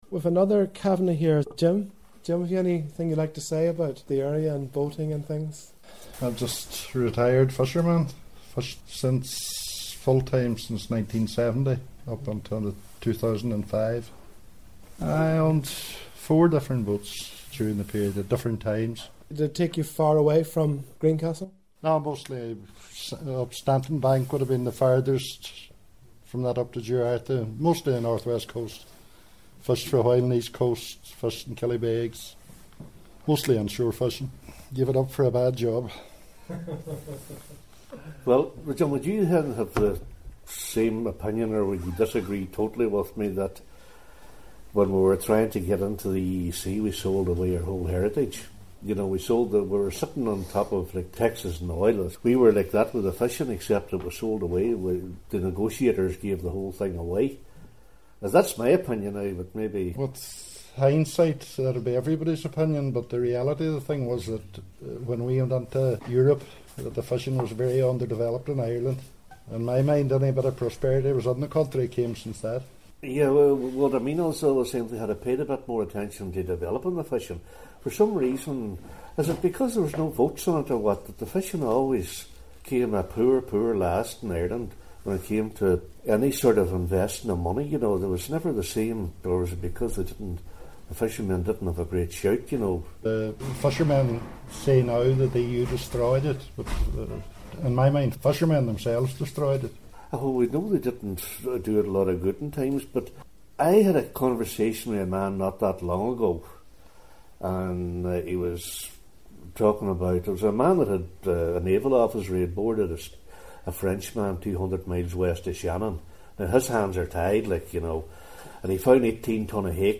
A lively and still topical discussion took place regarding the perceived inequities of the EU's fishing policy